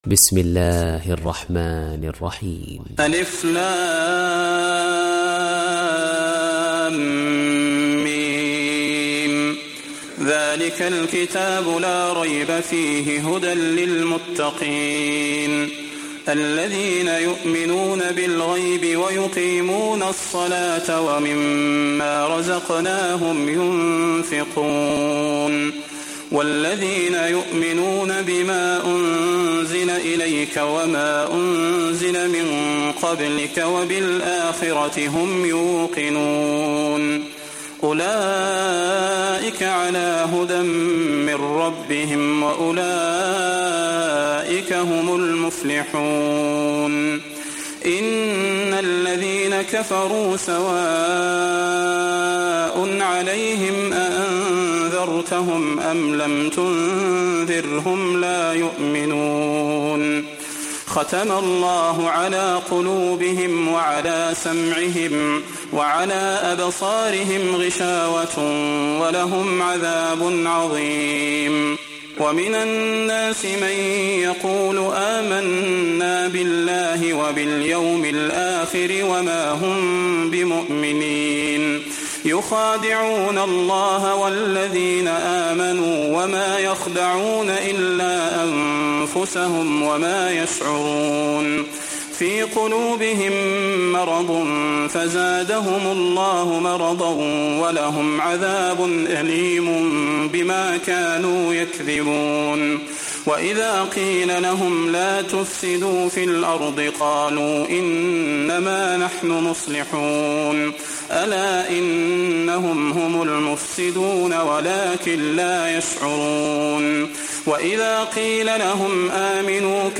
Bakara Suresi İndir mp3 Salah Al Budair Riwayat Hafs an Asim, Kurani indirin ve mp3 tam doğrudan bağlantılar dinle